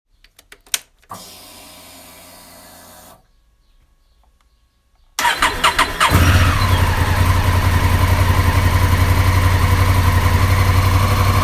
Quitschgeräusch beim Starten - Honda Africa Twin Forum
ich habe dir mal die Startgeräusche meiner crf1000 aufgenommen, wenn du das Geräusch am Anfang vor dem Start meinst dann denke ich das es aus dem Bereich des DCT Stellmotors kommt und ist normal.